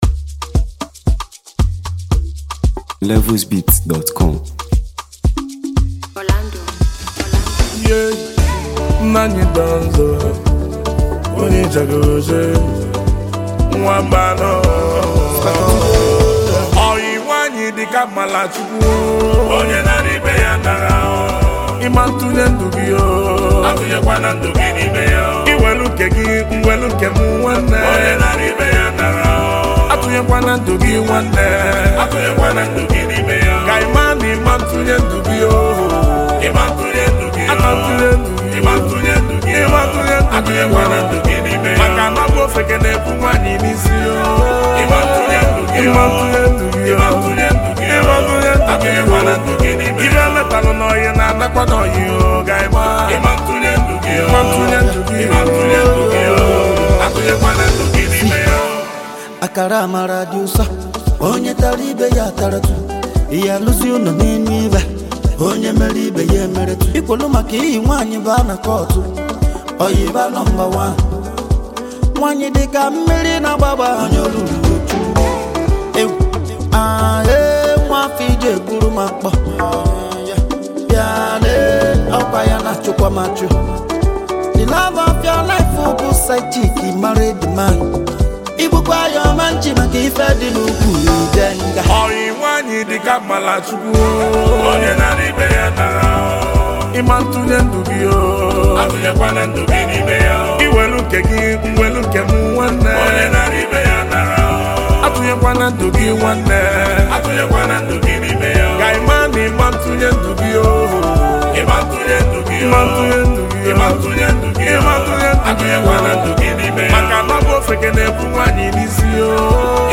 Nigerian highlife